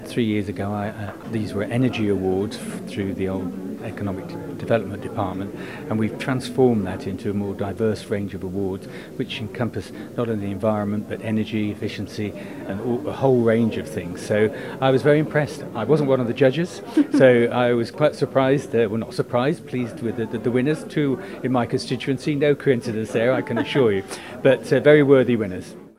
DEFA Minister Geoffrey Boot says it showcases how environmentally minded the Island is: